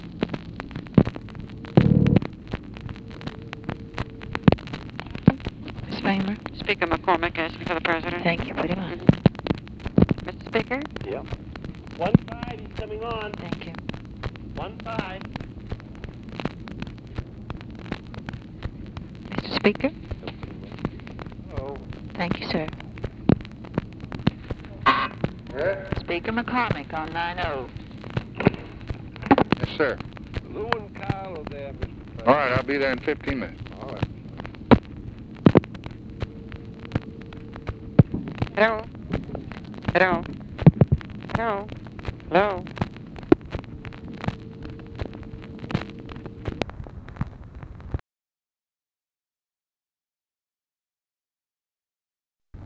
Conversation with JOHN MCCORMACK, March 9, 1964
Secret White House Tapes